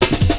Pickup 02.wav